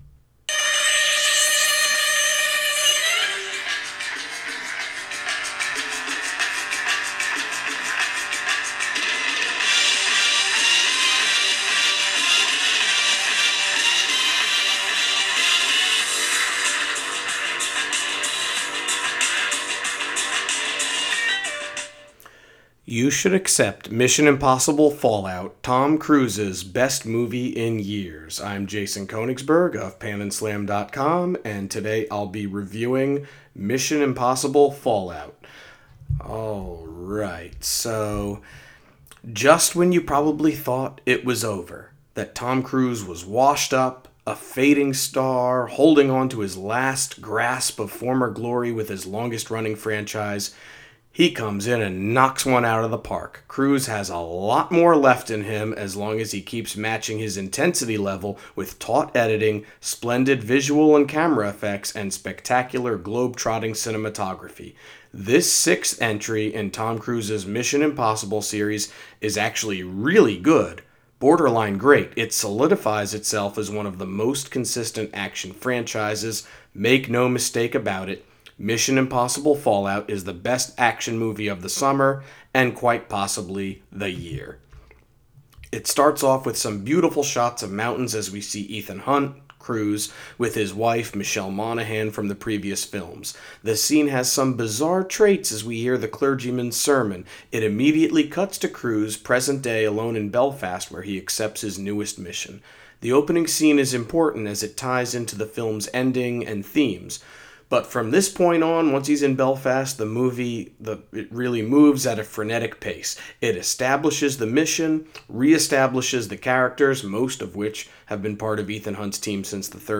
Movie Review: Mission: Impossible-Fallout